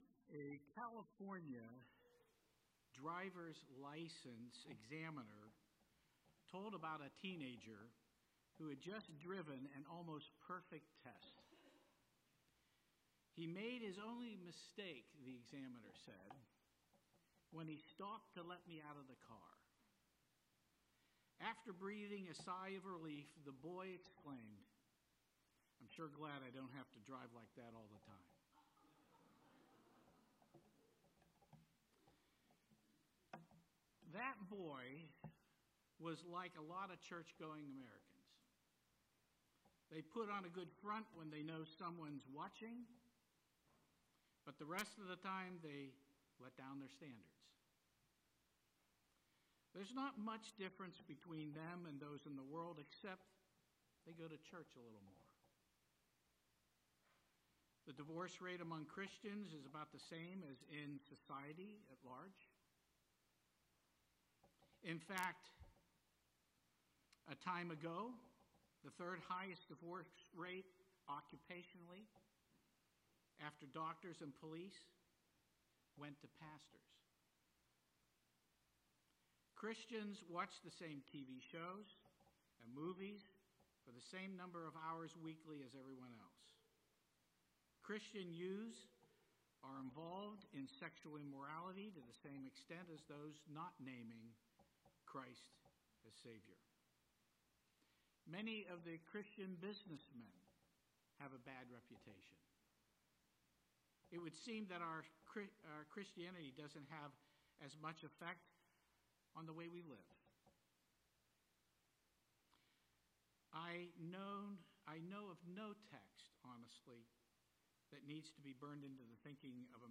Sermon
2025 at First Baptist Church in Delphi, Indiana.